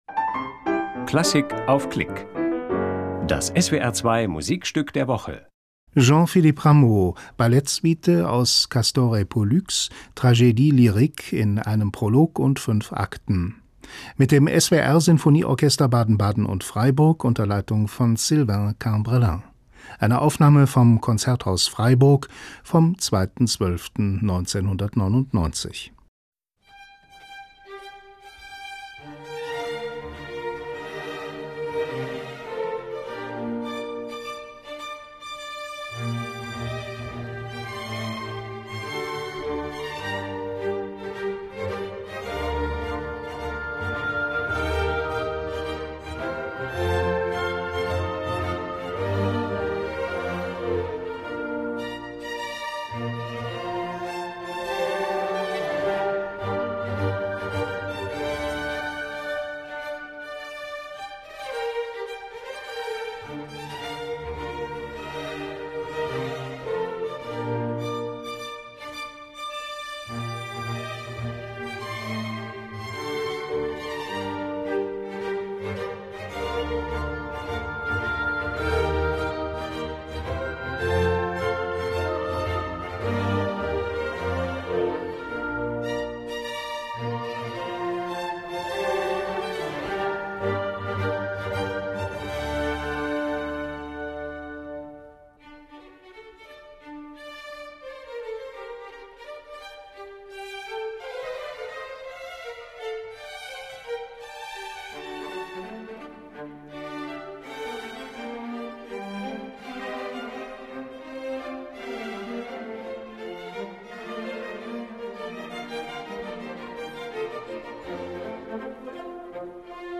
Ouvertüre - Gavotte - Tambourin - Chaconne, , Konzert in Freiburg vom 02.12.1999
SWR Sinfonieorchester Baden-Baden und Freiburg, Leitung: Sylvain Cambreling, Musikstück der Woche
Ouvertüre - Gavotte - Tambourin - Chaconne,